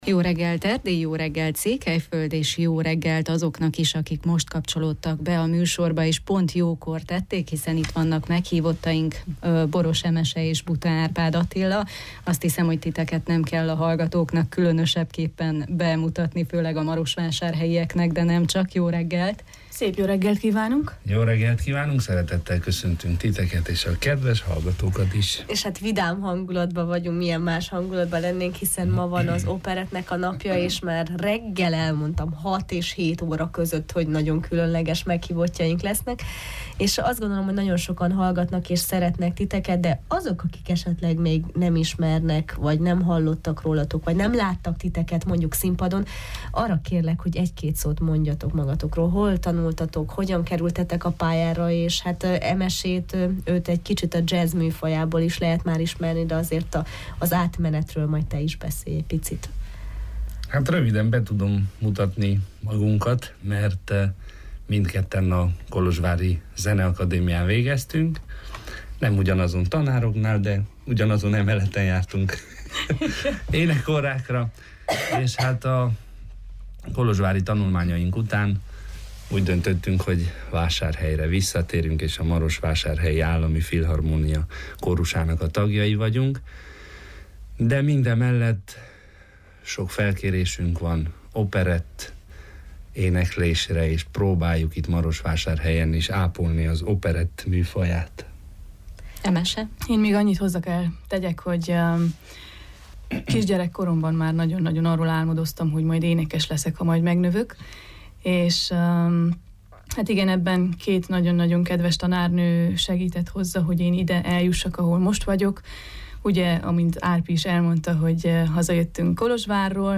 Élő zenével ünnepeltük a Magyar Operett Napját!